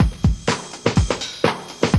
JUNGLEBRE00R.wav